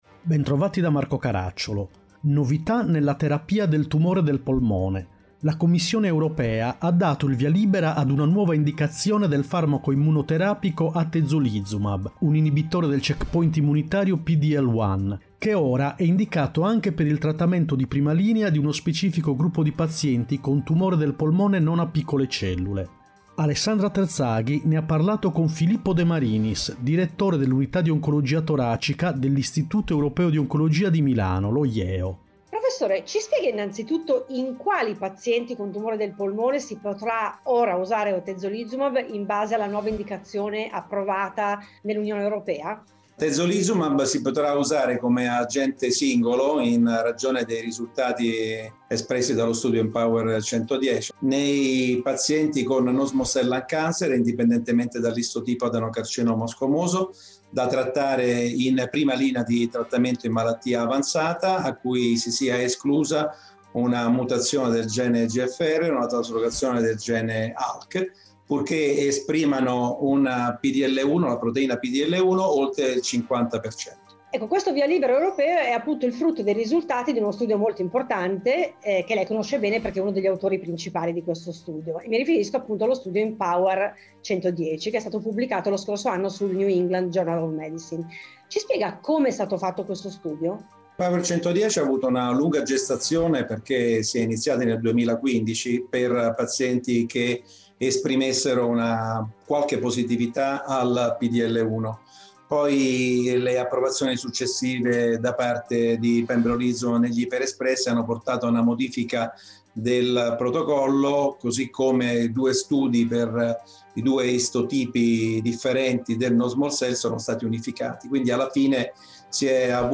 Puntata con sigla